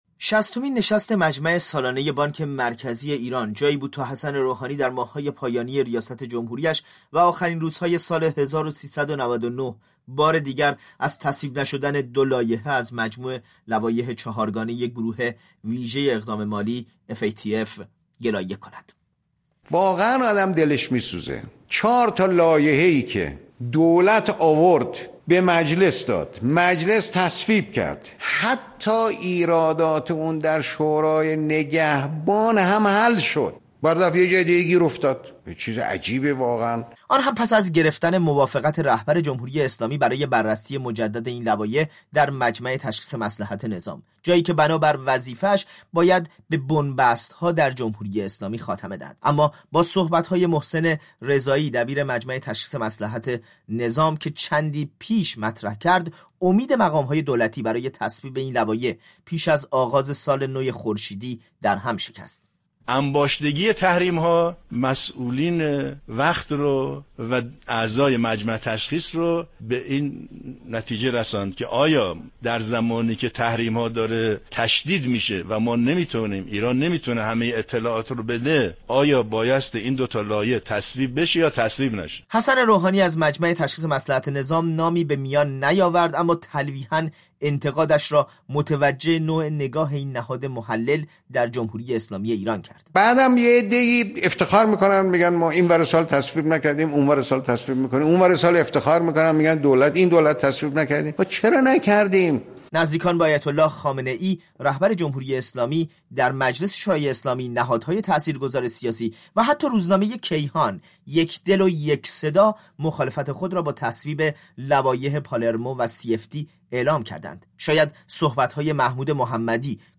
در گزارشی به تصویب نشدن عضویت ایران در اف ای تی اف و تاثیر آن بر اقتصاد شهروندان ایرانی در آستانه نوروز پرداخته است.